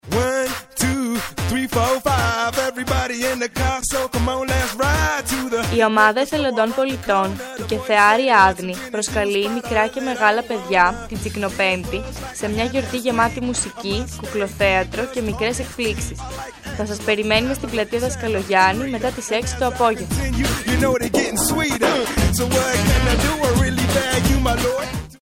Σπότ (394.29 KB)